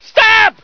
scream11.ogg